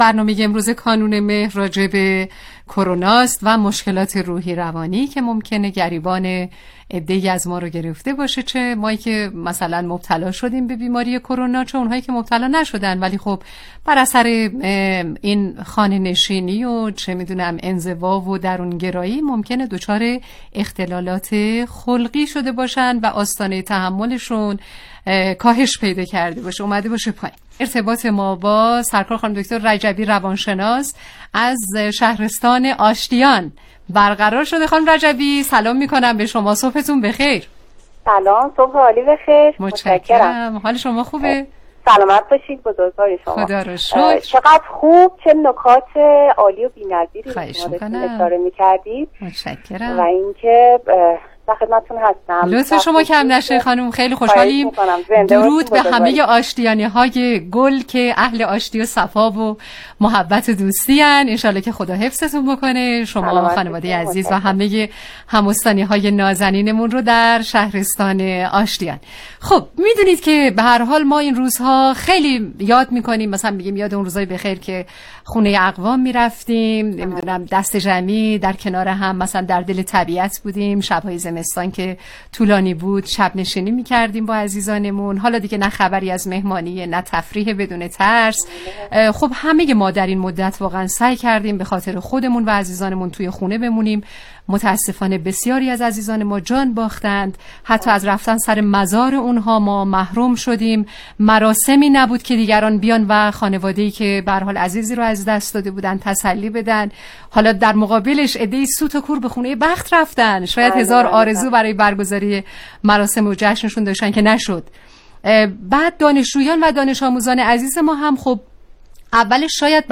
برنامه رادیویی کانون مهر با موضوع افسردگی در دوران کرونا